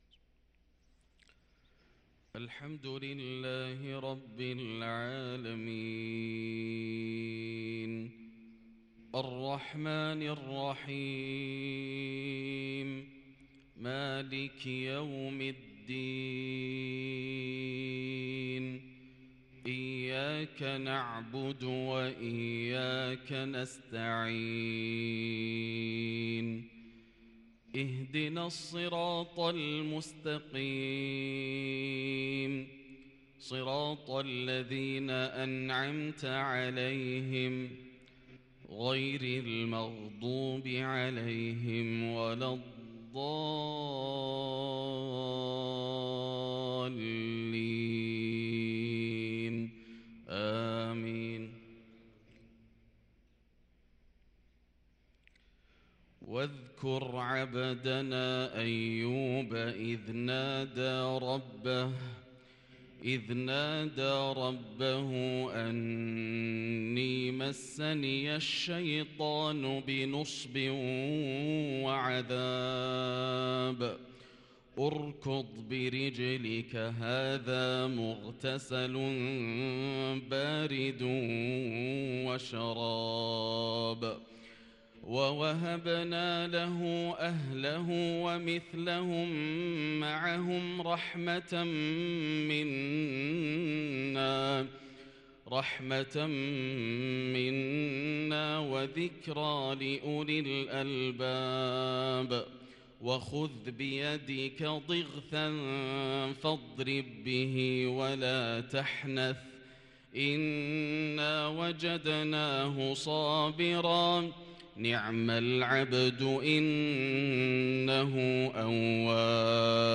صلاة الفجر للقارئ ياسر الدوسري 18 ربيع الآخر 1444 هـ
تِلَاوَات الْحَرَمَيْن .